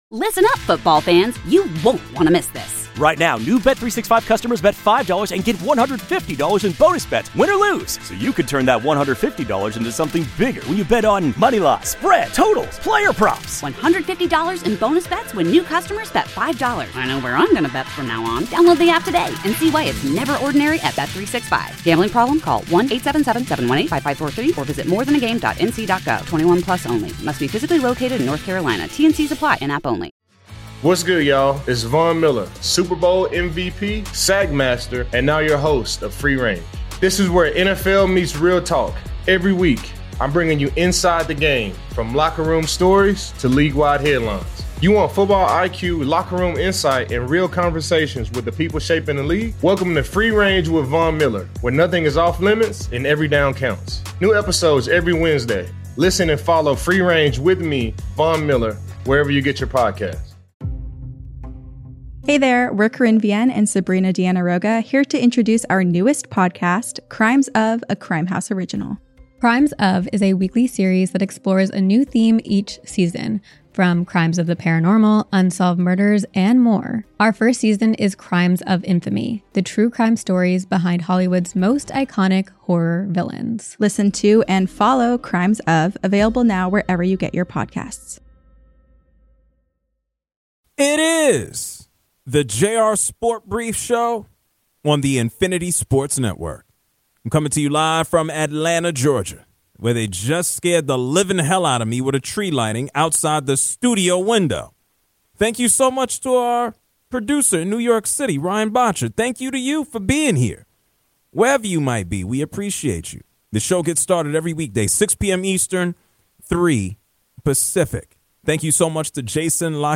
| A caller loves gambling on college basketball |